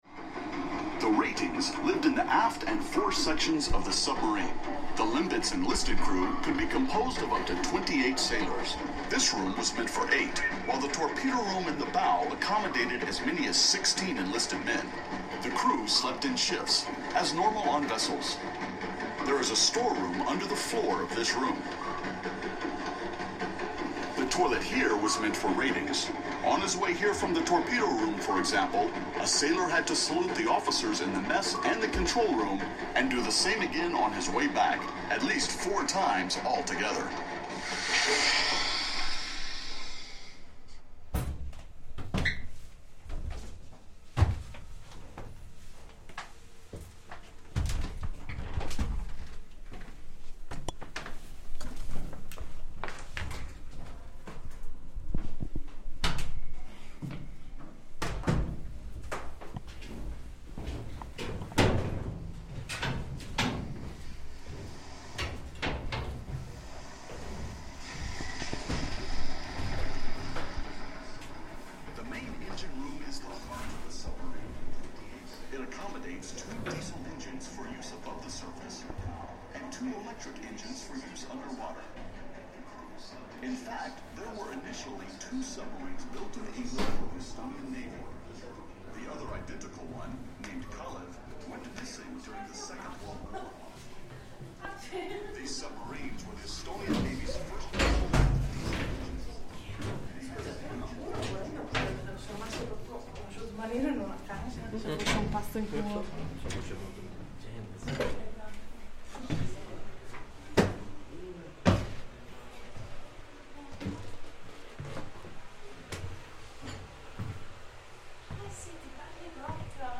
At the Lennusadam museum in Tallinn, there is the Lembit submarine available for visitors to explore. This soundscape takes us from the aft to the fore of the submarine, listening to the audio descriptions about life aboard a submarine, the pops of the sonar scanner, and climbing the metal ladder back out into the cavernous museum space.